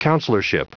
Prononciation du mot counselorship en anglais (fichier audio)
Prononciation du mot : counselorship